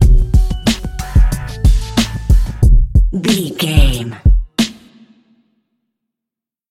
Aeolian/Minor
drum machine
synthesiser
electric piano
hip hop
Funk
neo soul
acid jazz
confident
energetic
bouncy
funky